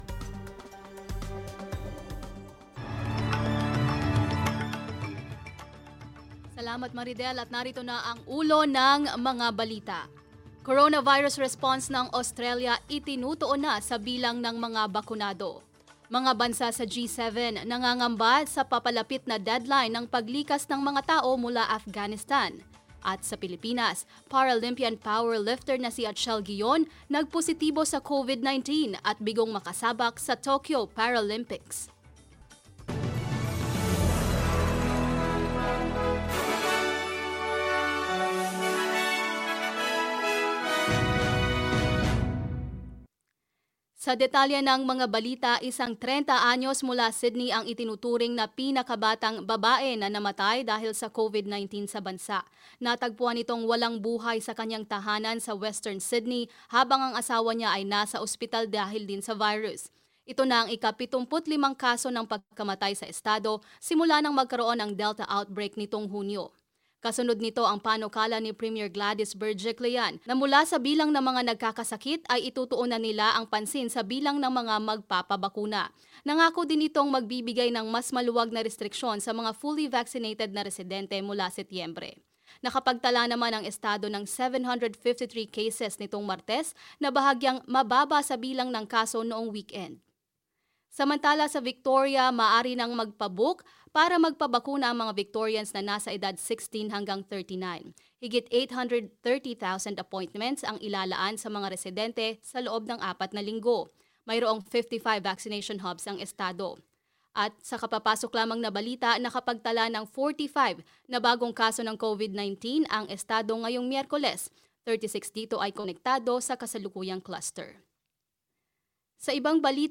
SBS News in Filipino, Wednesday 25 August